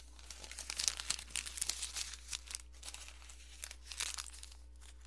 皱纹纸
描述：揉碎一张纸
Tag: 皱巴巴的纸 弄皱